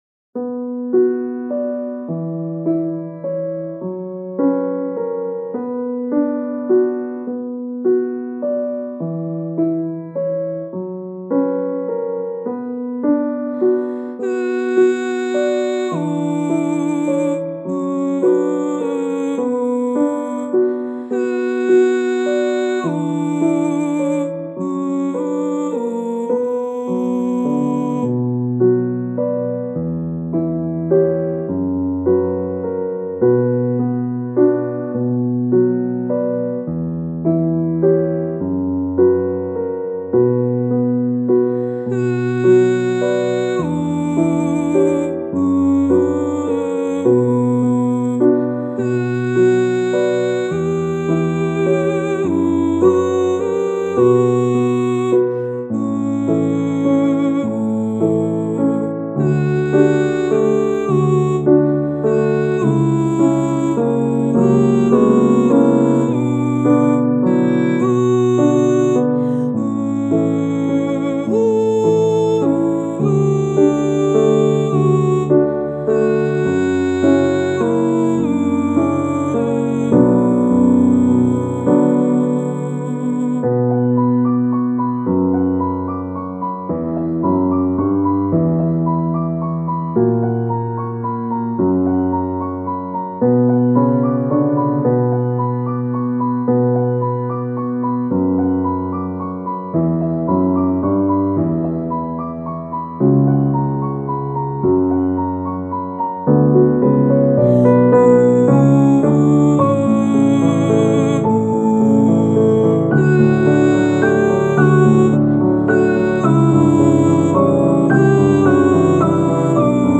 レクイエムをイメージしたピアノと女性ボイスのクラシカルで悲しい曲です。
♩=free
ピアノ,ボイス